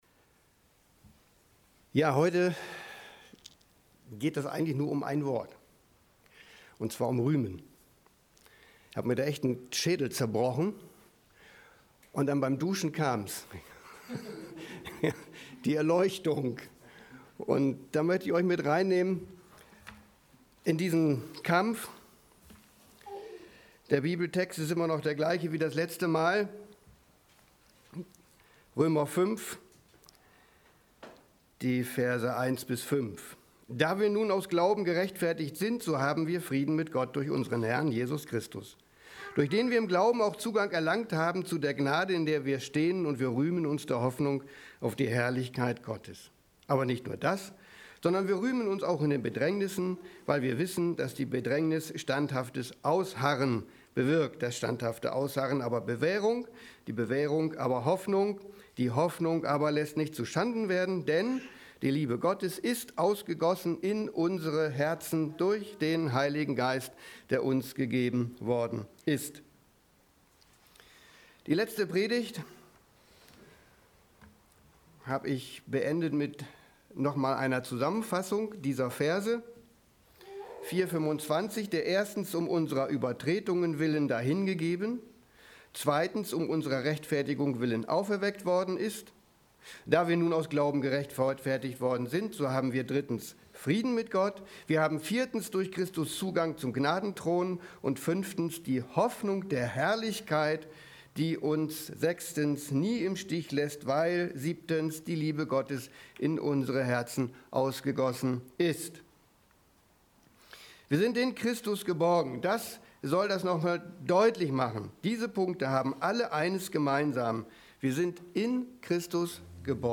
Predigt_07.06.2020_Römer_5_Rühmen